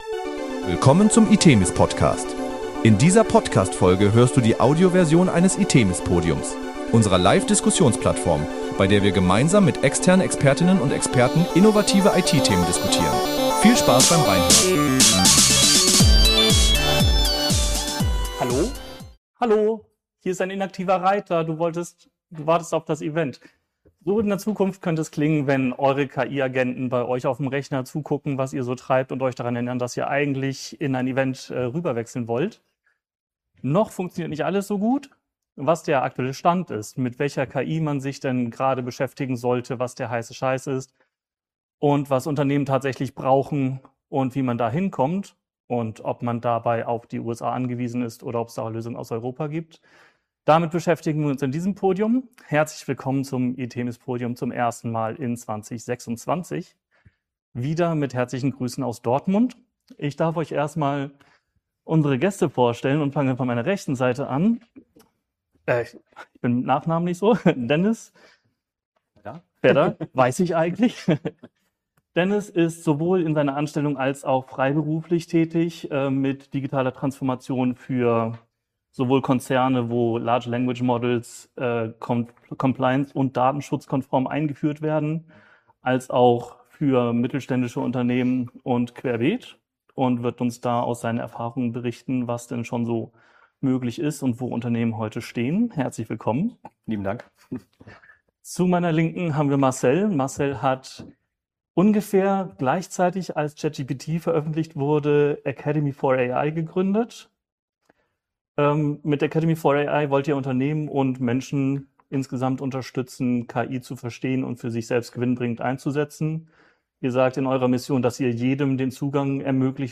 Beschreibung vor 2 Tagen In dieser Folge des itemis PODCAST hört ihr die Audioversion des itemis PODIUMS „Kampf der Giganten“.